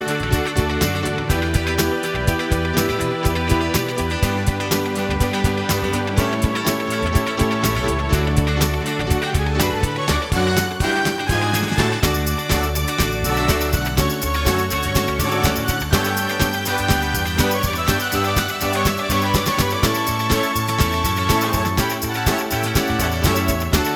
no bass or Backing Vocals Pop (1970s) 3:20 Buy £1.50